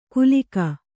♪ kulika